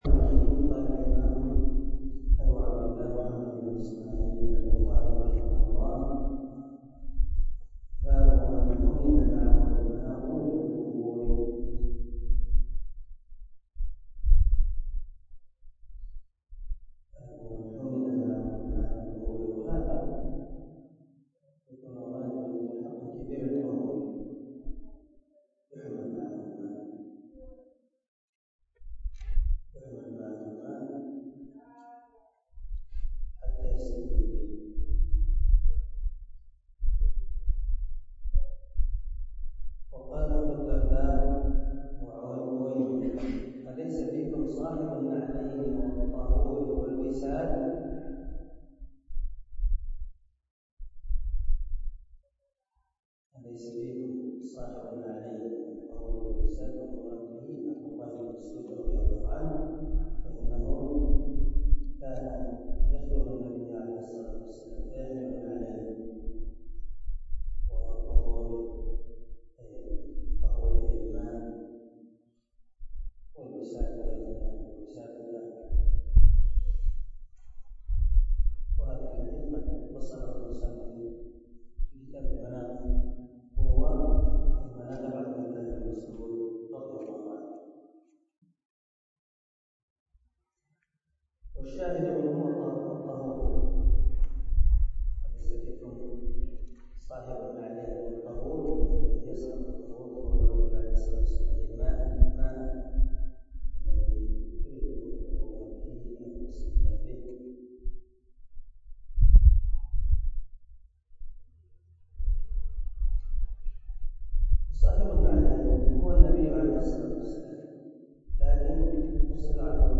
140الدرس 16 من شرح كتاب الوضوء حديث رقم ( 151 - 152 ) من صحيح البخاري